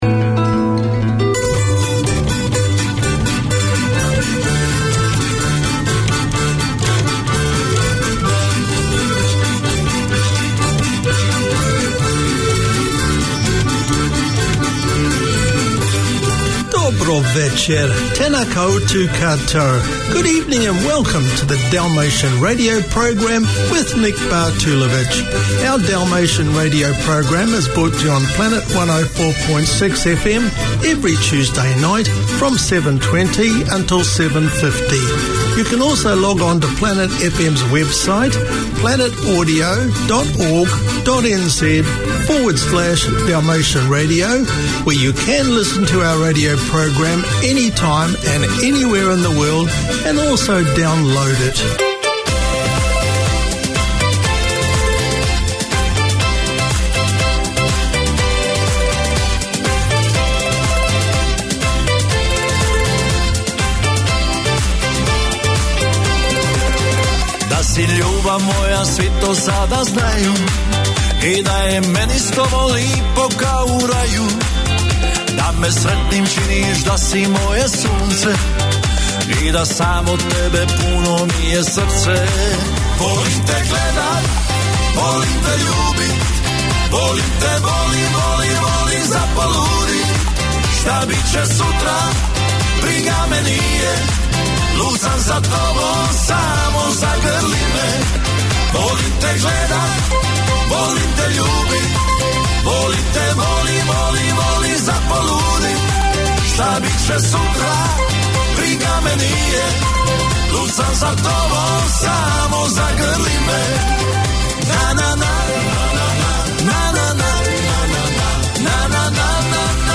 We present Society news and explore the achievements of Kiwis of Dalmatian descent. The music selected from around the former Yugoslavia is both nostalgic and modern.